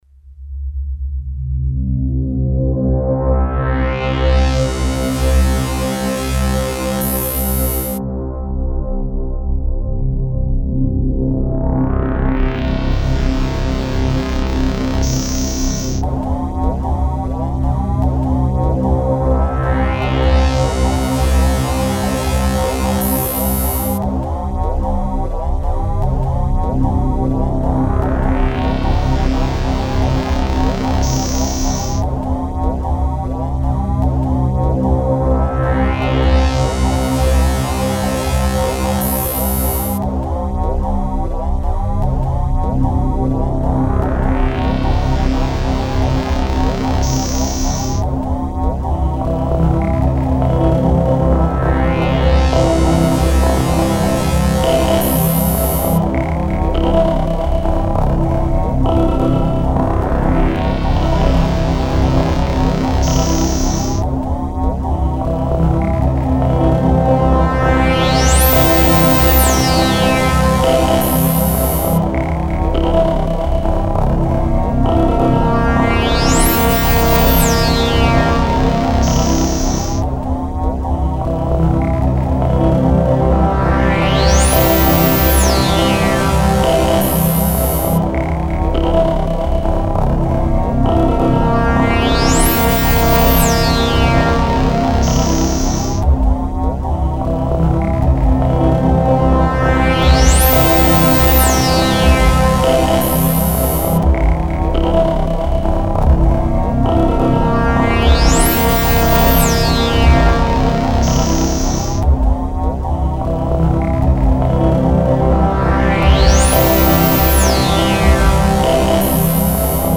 Genre Synthpop